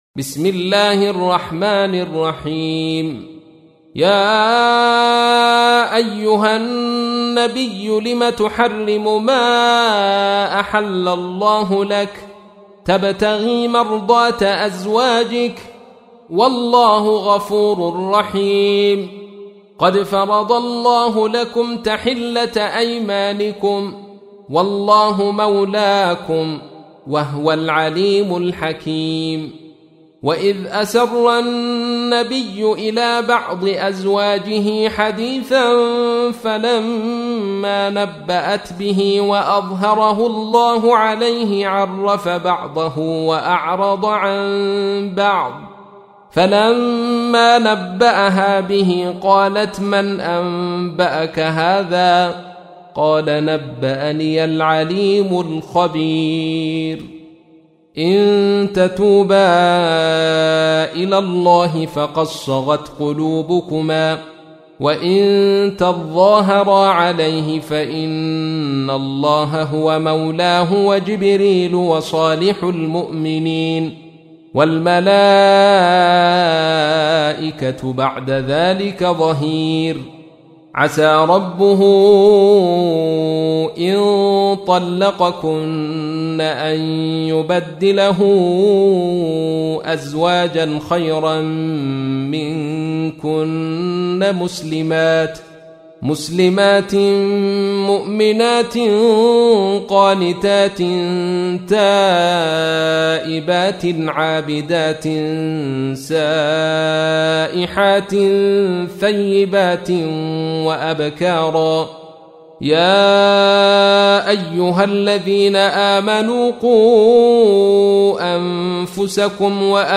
تحميل : 66. سورة التحريم / القارئ عبد الرشيد صوفي / القرآن الكريم / موقع يا حسين